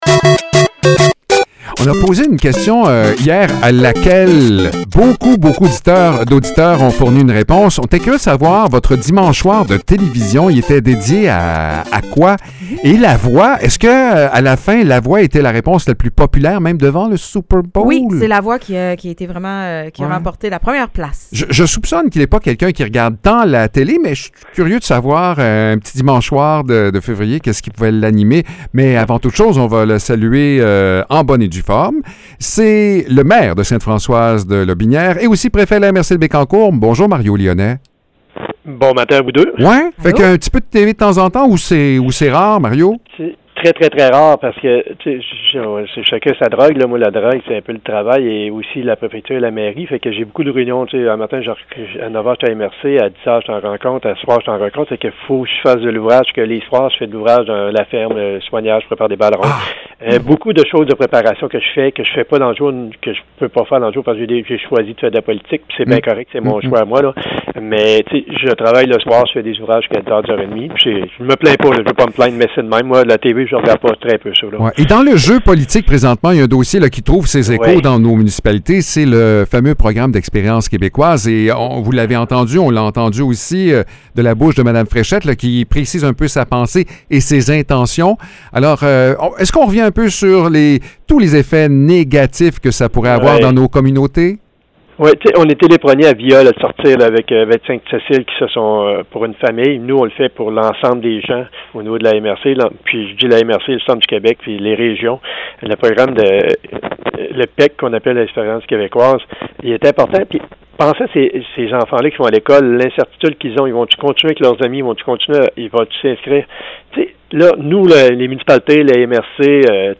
Mario Lyonnais, maire de Sainte-Françoise et préfet de la MRC de Bécancour, revient sur un malheureux accident qui rappelle l’importance de bien sécuriser nos systèmes de chauffage. Il invite la population à vérifier régulièrement leurs installations afin de prévenir les risques d’incendie.